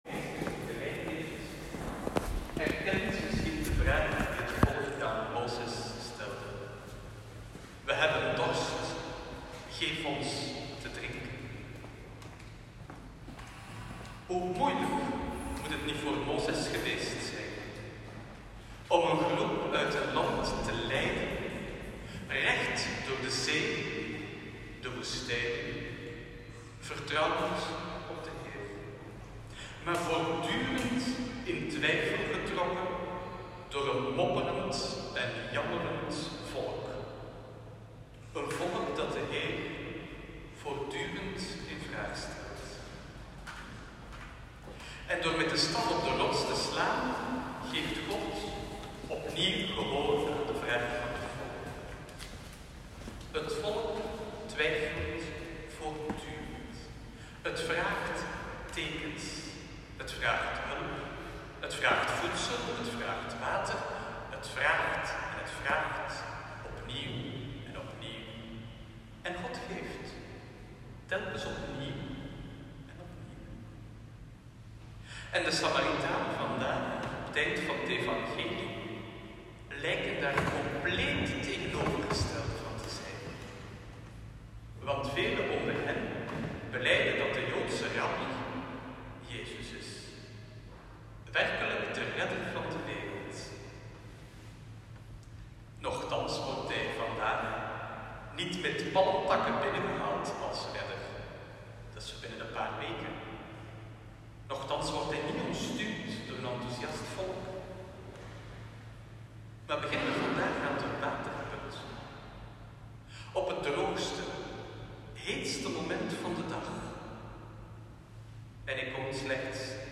Viering 12 maart 2023
Preek.m4a